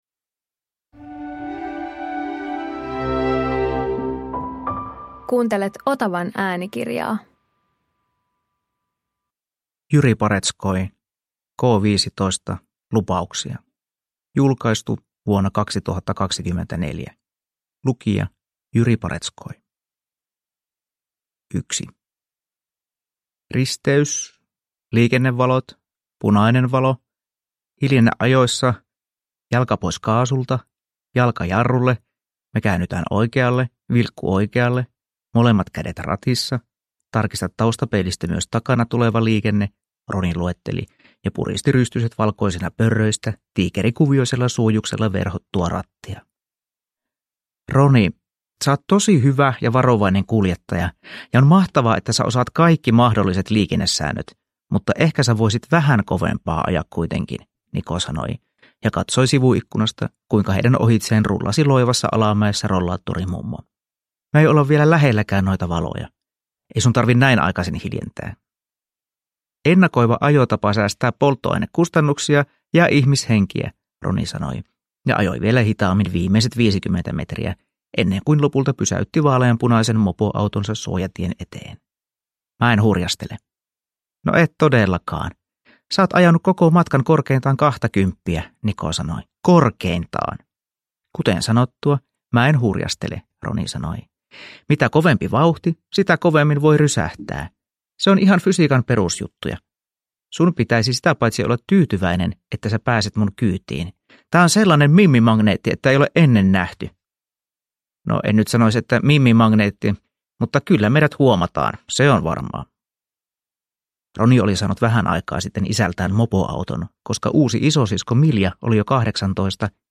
K15 - Lupauksia – Ljudbok